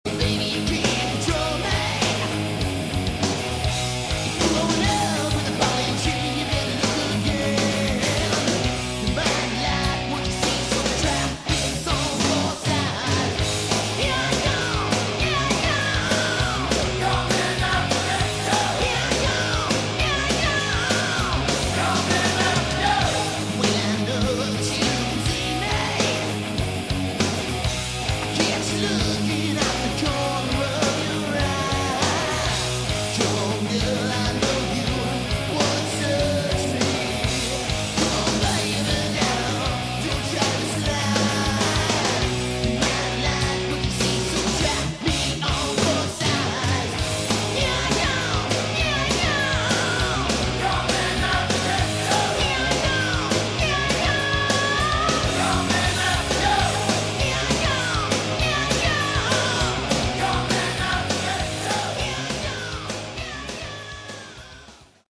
Category: Sleaze Glam
vocals
guitar
bass
drums